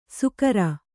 ♪ sukara